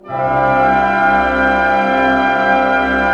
Index of /90_sSampleCDs/Roland - String Master Series/ORC_ChordCluster/ORC_Pentatonic